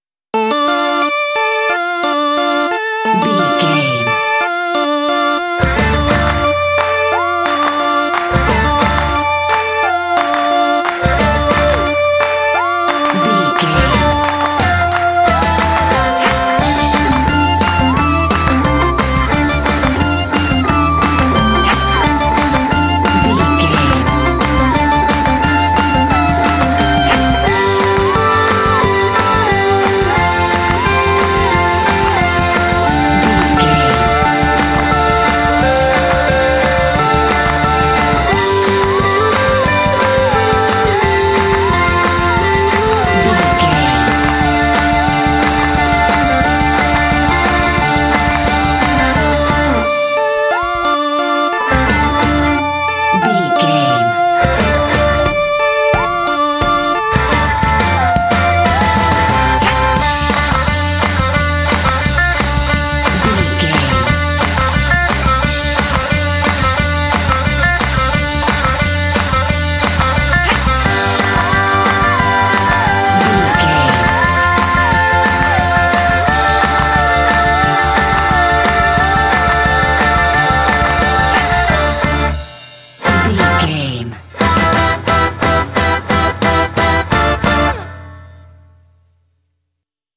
Aeolian/Minor
Fast
tension
energetic
lively
organ
bass guitar
synthesiser
electric guitar
drums
percussion
indie